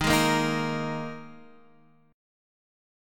Ebsus4 chord